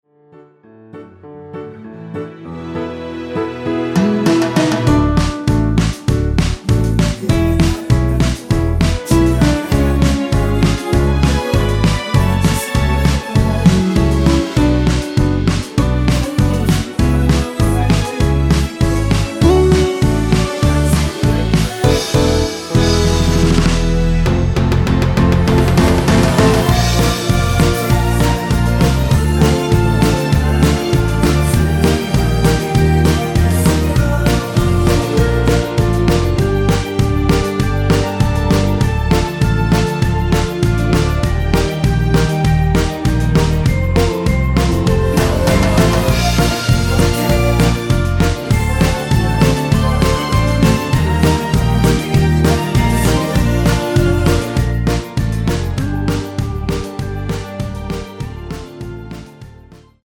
(-2) 내린 코러스 포함된 MR 입니다.
앞부분30초, 뒷부분30초씩 편집해서 올려 드리고 있습니다.